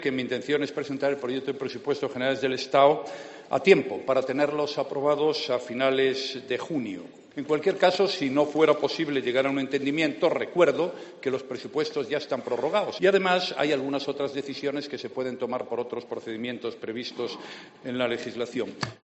Así se ha dirigido el secretario general de Podemos al presidente del Gobierno, a quien ha preguntado en la sesión de control al Ejecutivo en el Congreso si "cuenta con apoyos para sacar adelante los presupuestos generales este año o tiene previsto prorrogarlos".